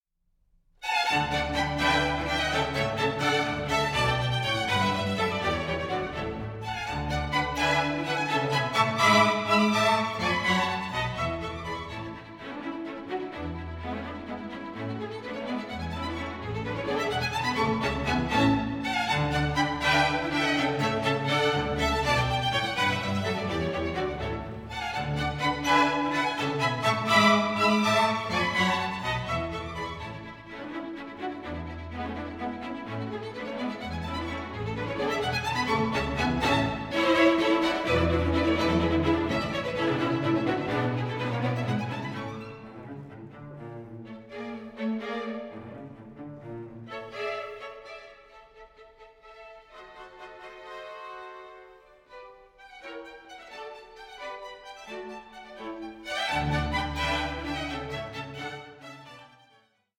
taut intensity and contrapuntal precision